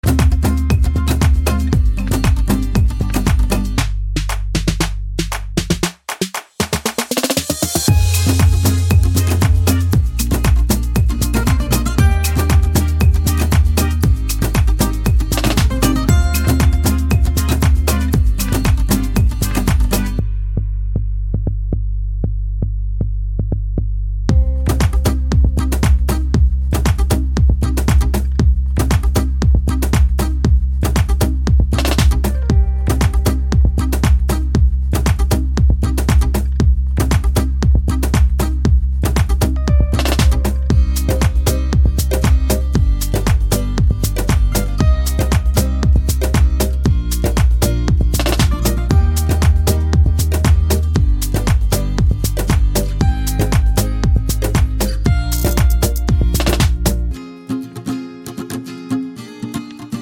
With Rapper Pop (2010s) 3:36 Buy £1.50